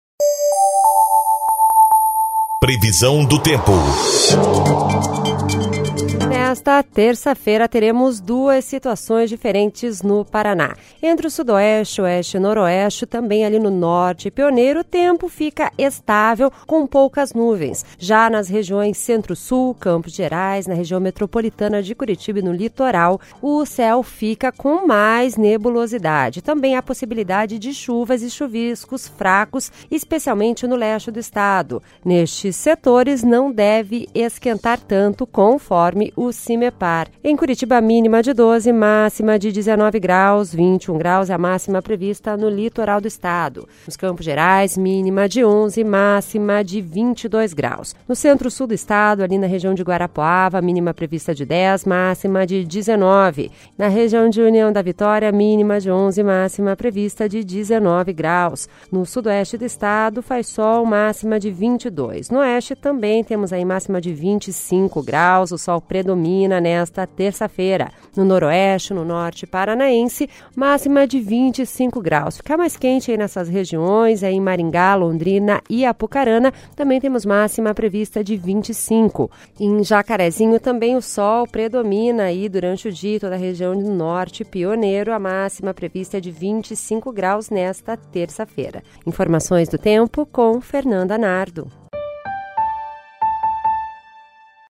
Previsão do Tempo (06/06)